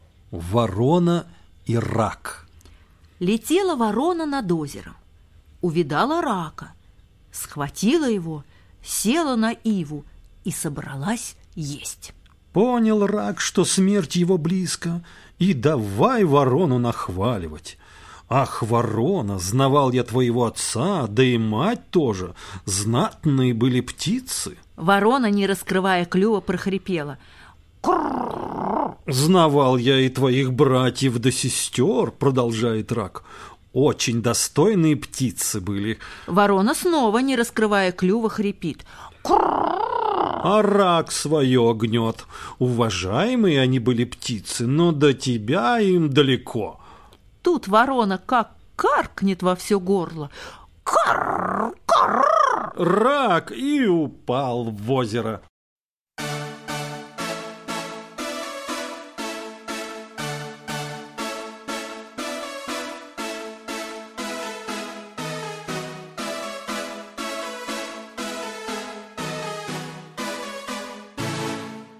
Ворона и рак - латышская аудиосказка - слушать онлайн